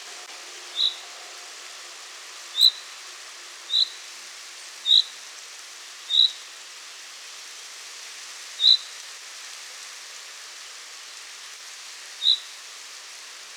common chaffinch
Fringilla coelebs